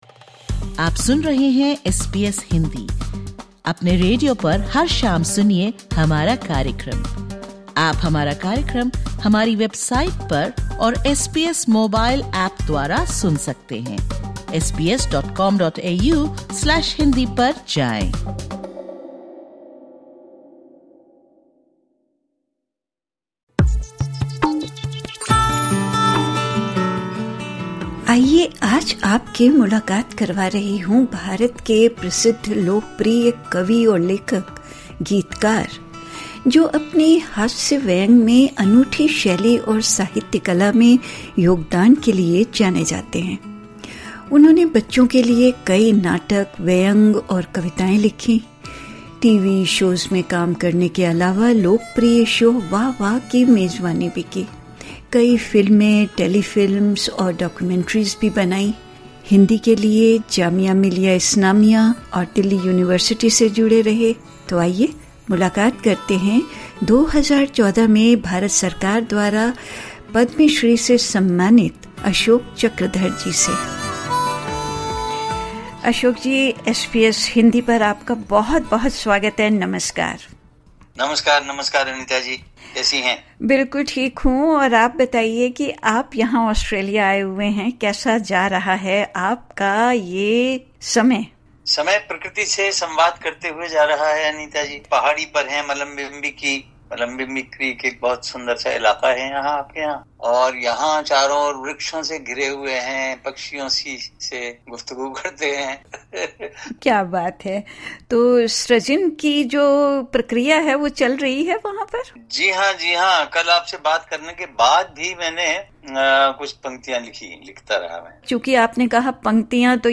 ऑस्ट्रेलिया यात्रा पर आये चक्रधर, एसबीएस हिन्दी के साथ इस मुलाकात में कविता के मूल विचार पर चर्चा करते हुये अपनी कवितायें भी सुना रहे हैं।